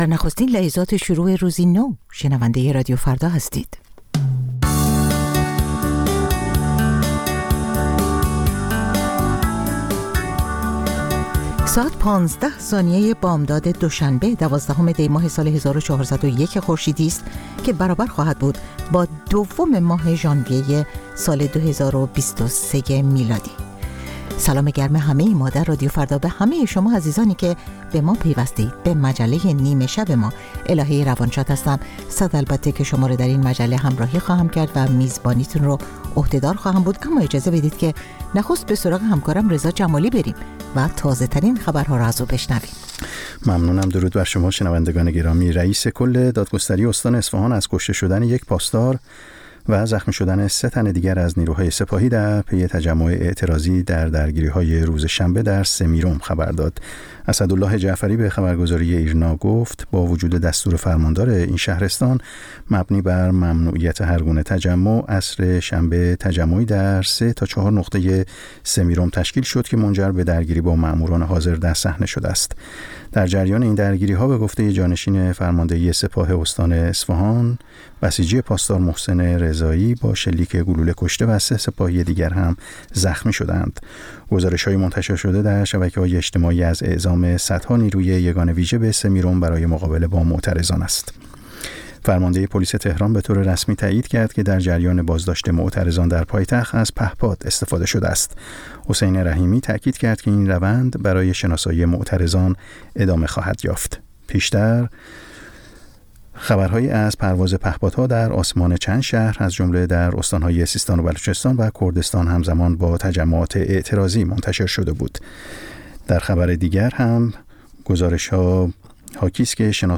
همچون هر روز، مجله نیمه شب رادیو فردا، تازه ترین خبر ها و مهم ترین گزارش ها را به گوش شما می رساند.